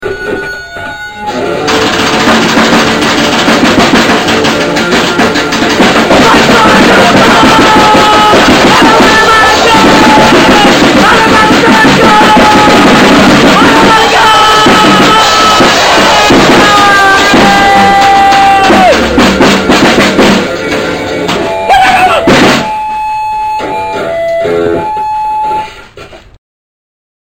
penn state's premiere grindcore band.